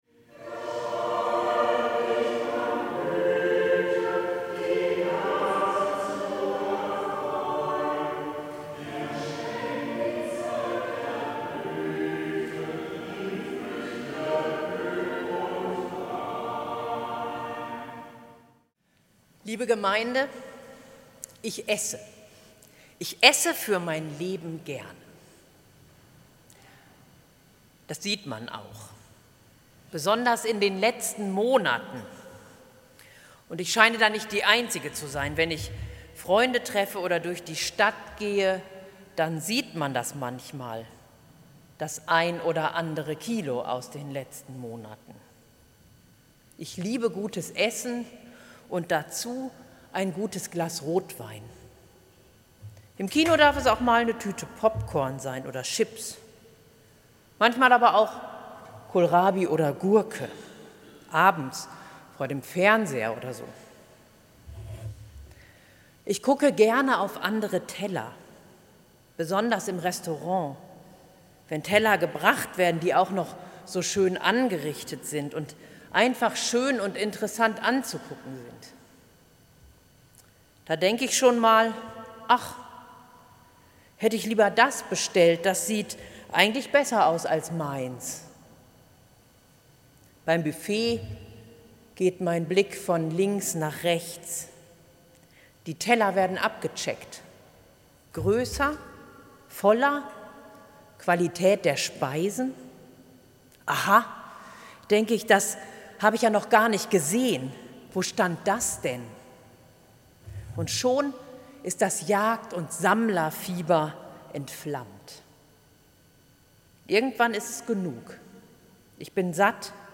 Predigt zu Erntedank